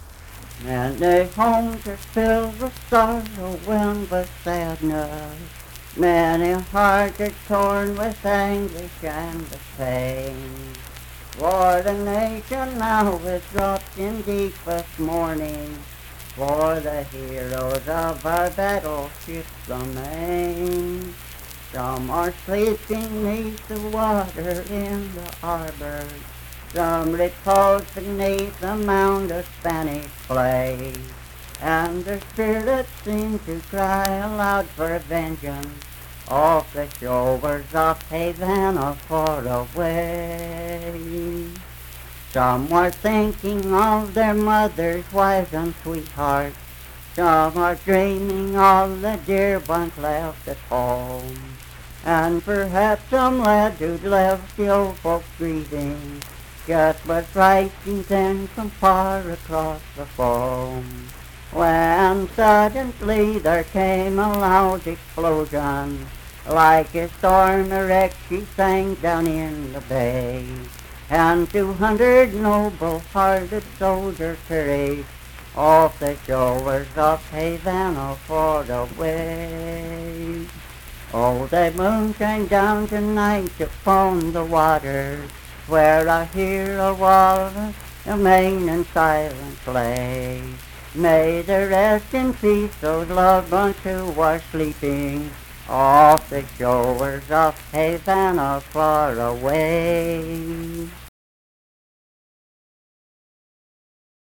Unaccompanied vocal music performance
War and Soldiers, Political, National, and Historical Songs
Voice (sung)